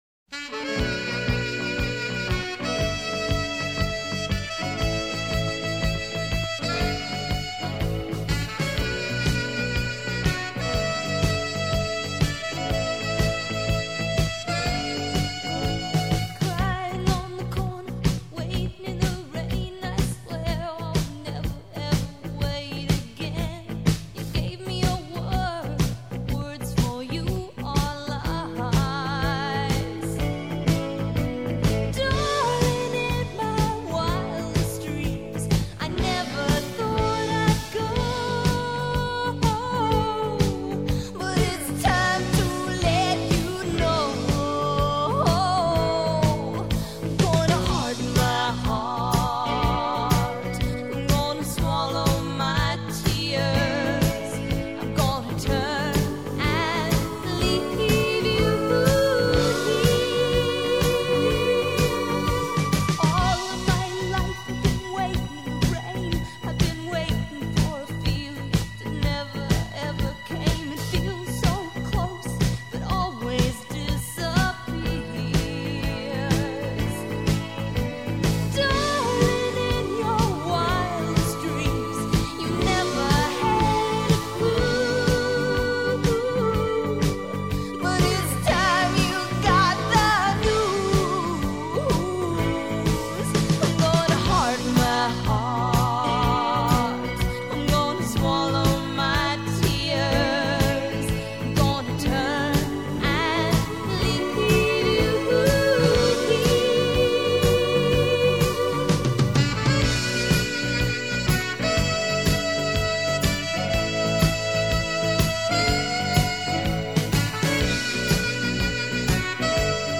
여성 록 보컬리스트